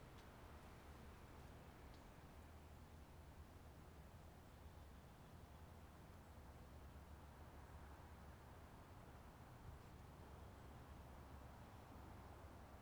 monte rio - quietOutdoorAmbience.wav